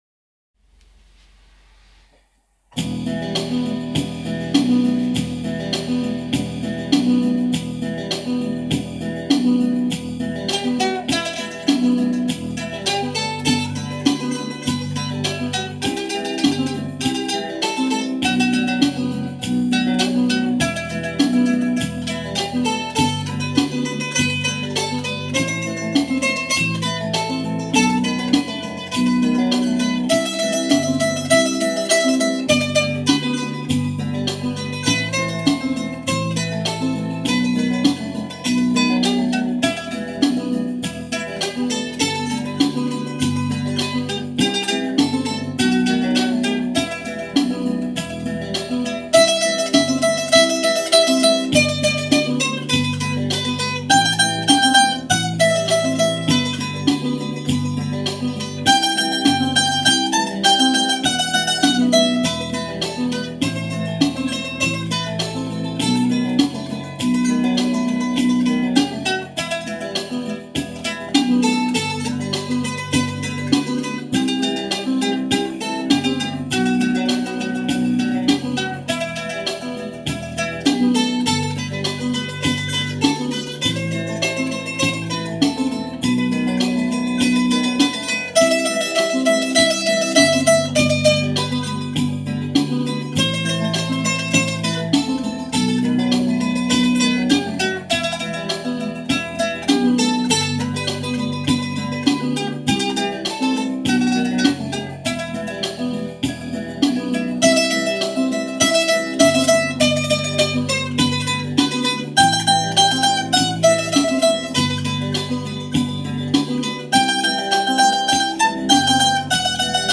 Hopp: egy mandolin!